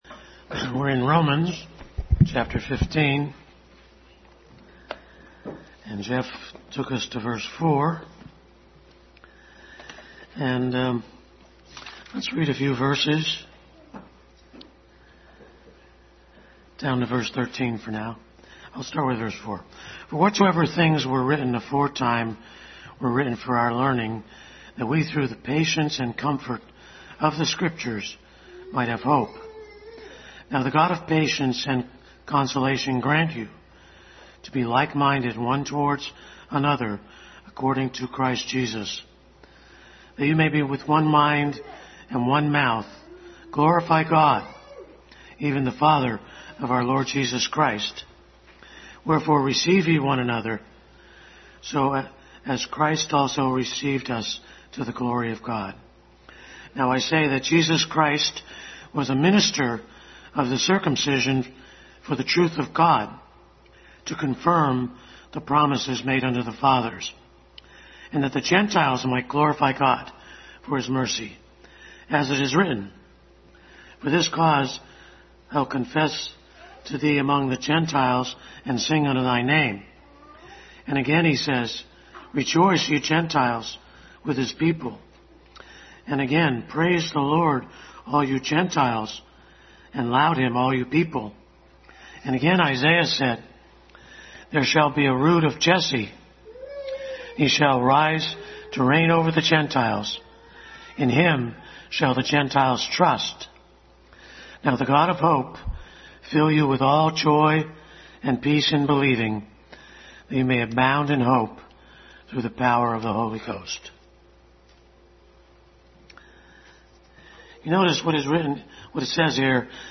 Adult Sunday School Class continued study in Romans.
Romans 15:4-13 Service Type: Sunday School Adult Sunday School Class continued study in Romans.